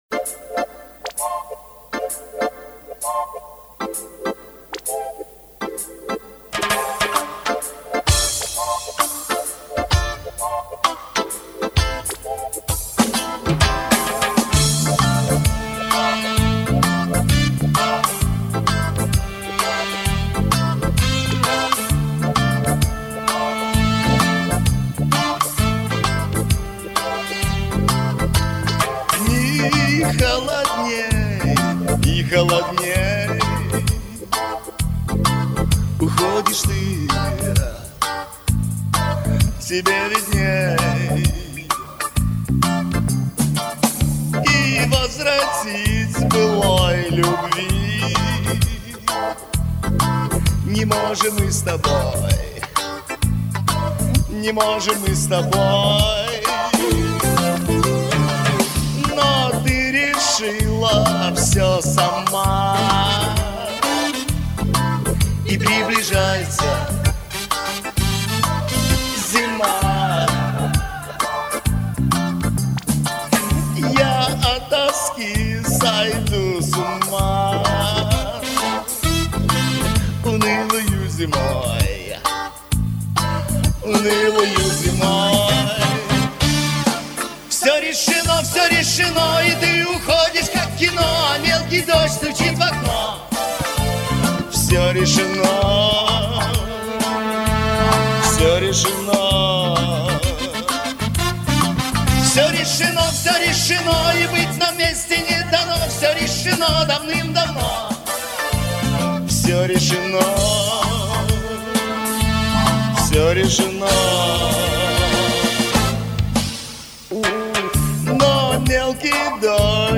«Споем регги?»